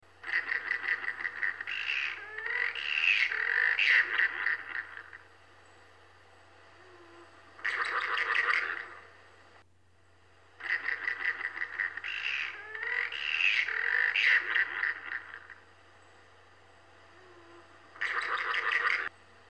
Bundanon Bioblitz 2014:  a guided nocturnal walk of spotlit looking and dark listening for local nightlife.